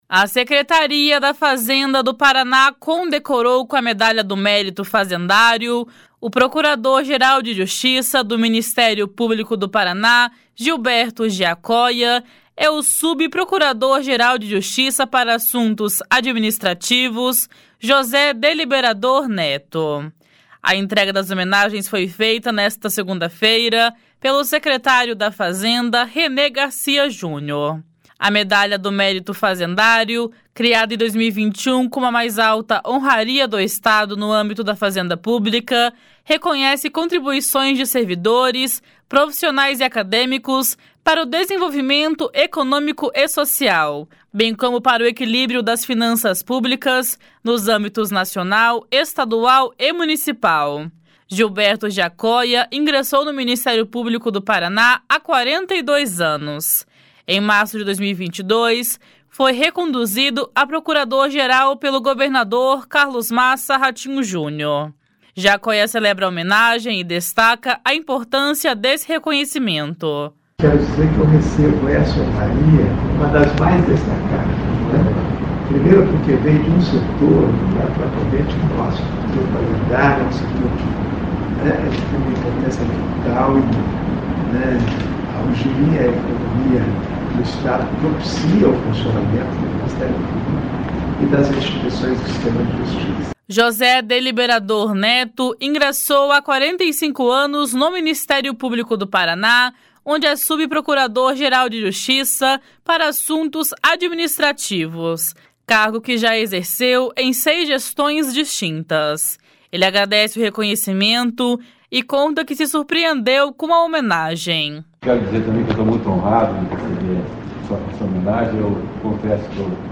// SONORA GILBERTO GIACOIA //
// SONORA JOSÉ DELIBERADOR NETO //
FAZENDA HOMENAGEIA PROCURADOR GERAL.mp3